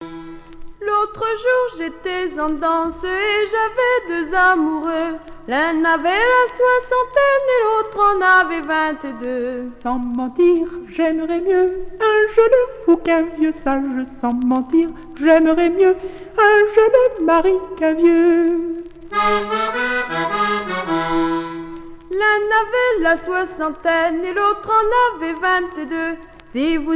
Chansons en dansant
Pièce musicale éditée